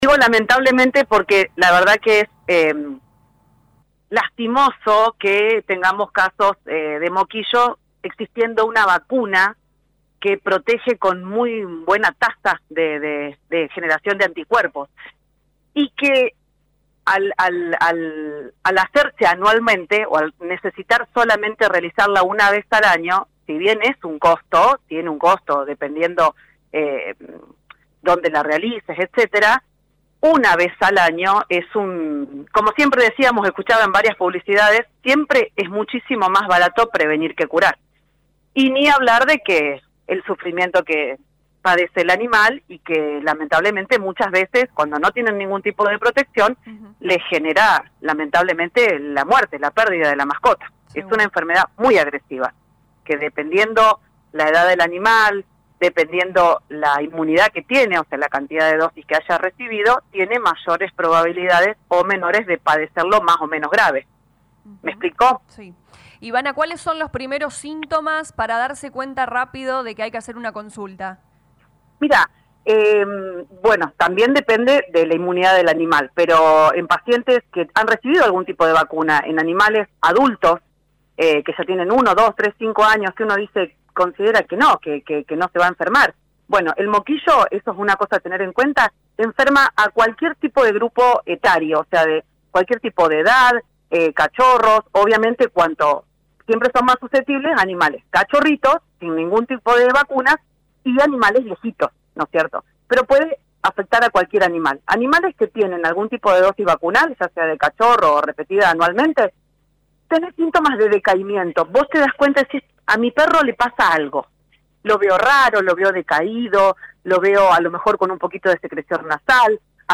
LA RADIO 102.9 FM dialogó con la médica veterinaria